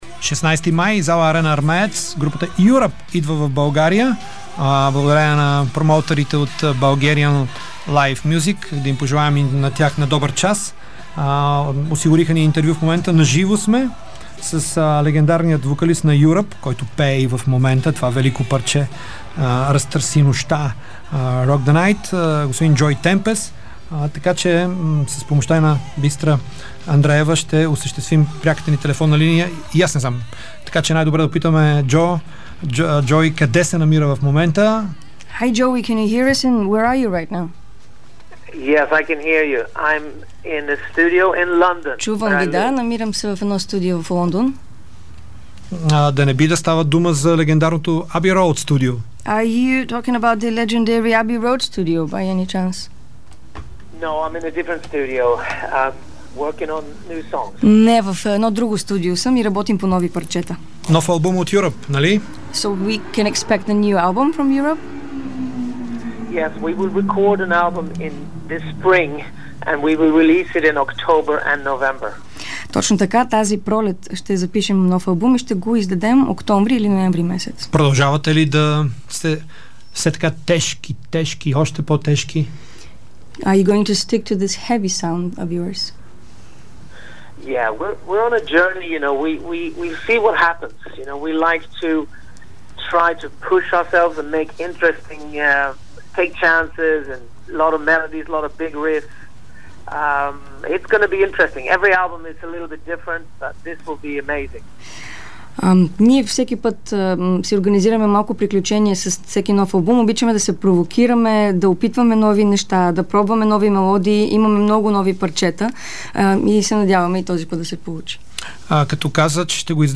За новия албум, за концерта в София, за работата днес в Лондон, чуйте разговора на Ники Кънчев с Джоуи Темпест: